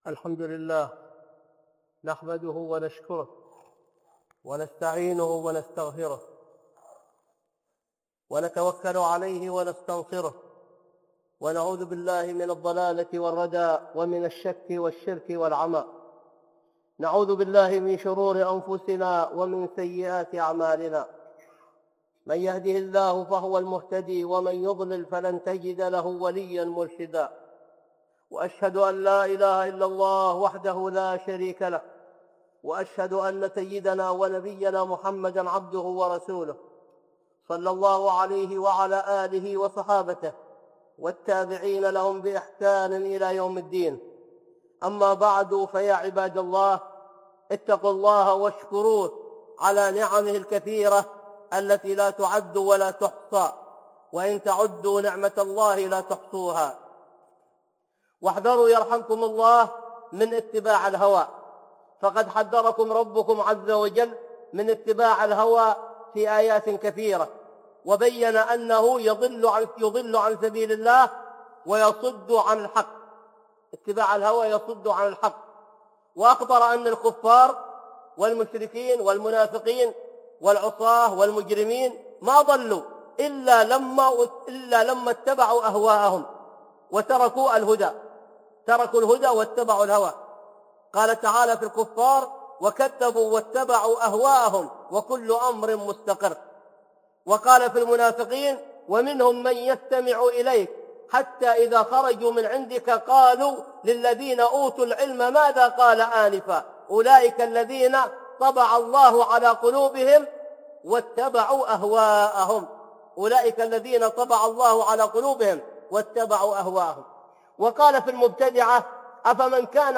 (خطبة جمعة) إتباع الهوى